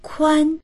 kuān 4 幅が広い；幅
kuan1.mp3